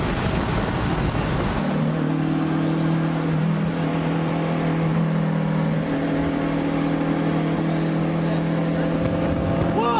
Kling-Audio-Eval / Sounds of other things /Engine /audio /27926.wav